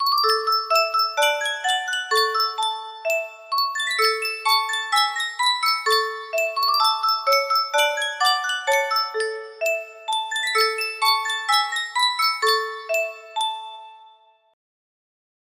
Sankyo Music Box - On the Banks of the Wabash IXS music box melody
Full range 60